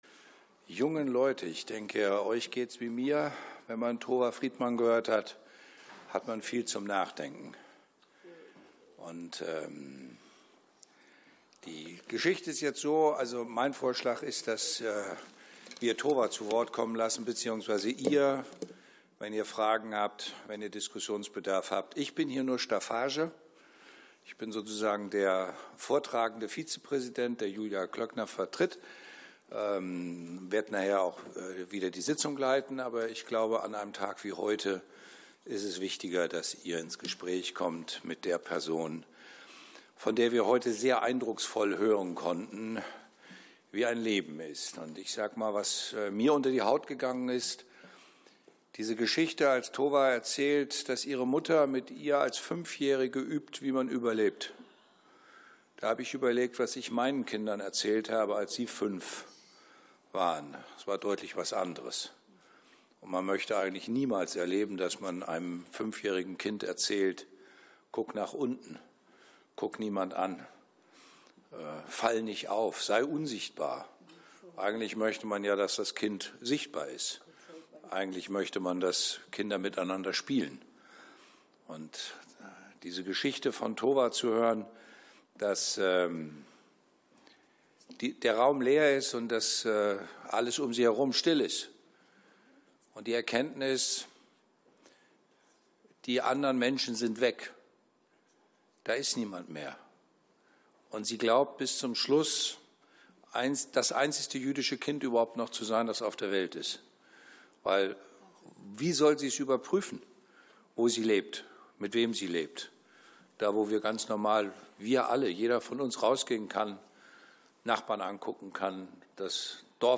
Podiumsdiskussion mit Tova Friedman und Bodo Ramelow